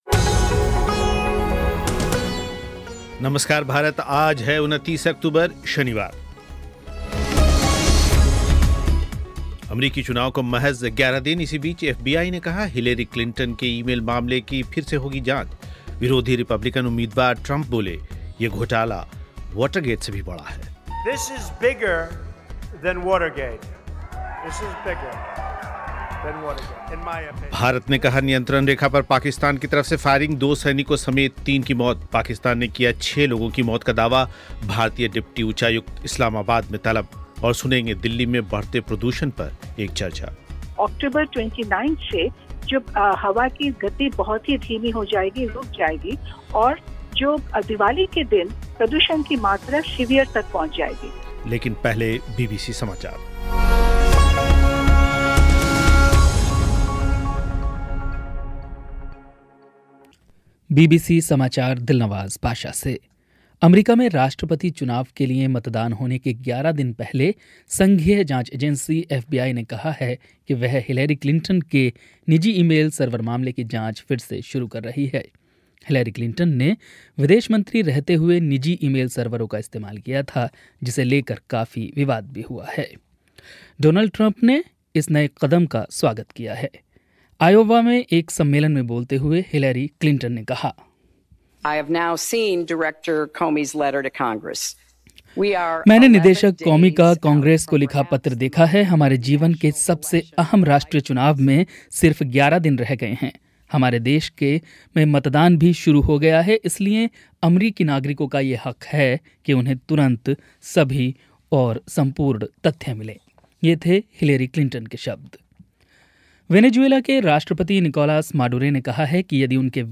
एक बातचीत
एक चर्चा